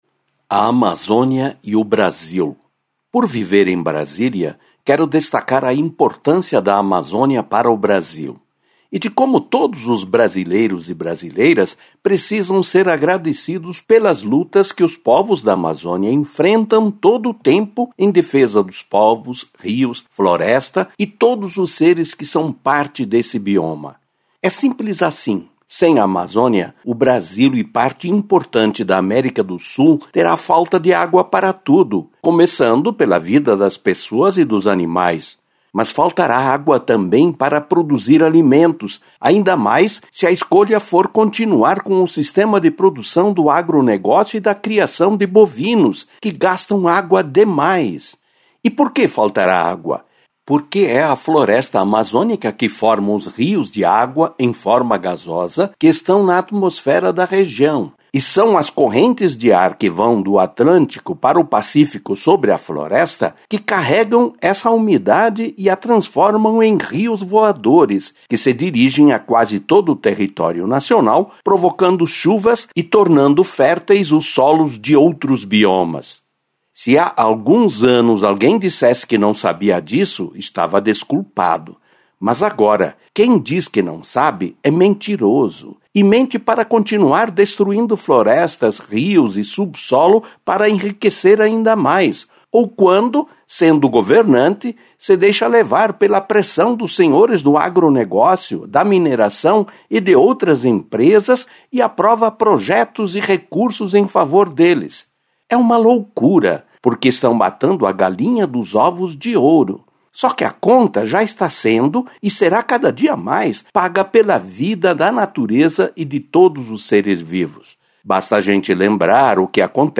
EDITORIAL-4.mp3